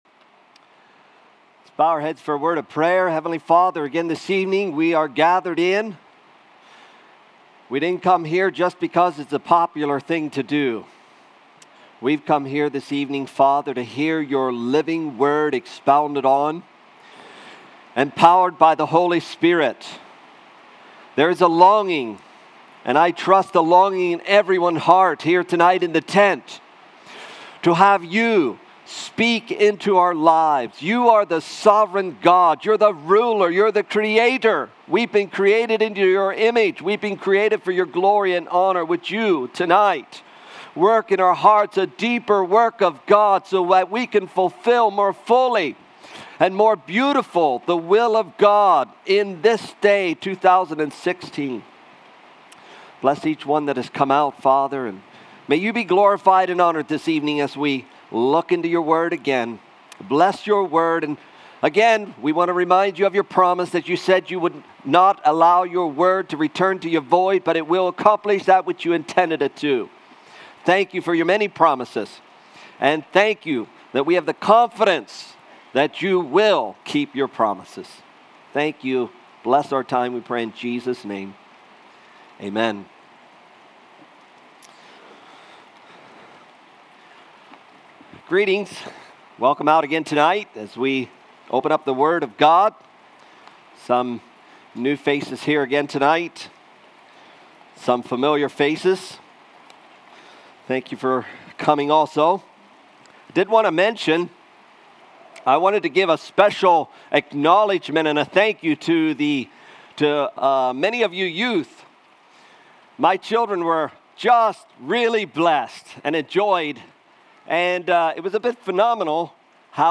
Guest Speaker
Tent Revival 2016